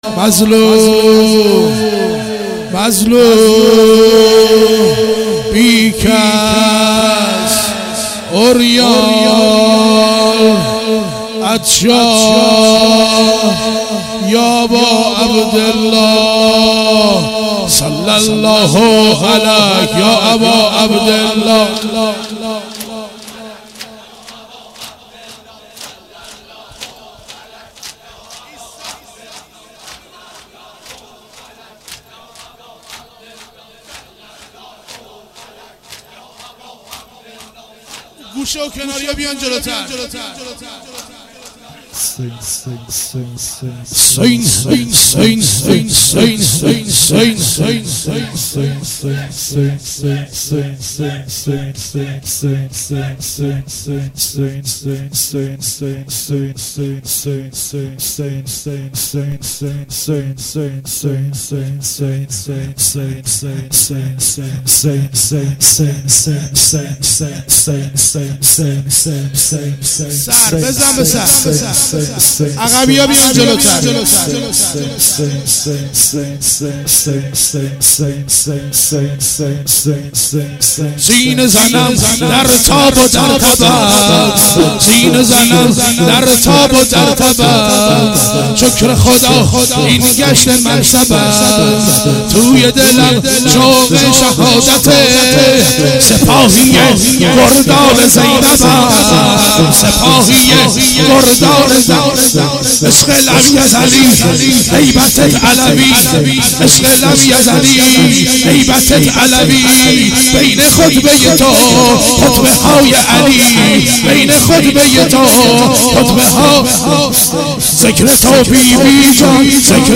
شور شب چهارم محرم 96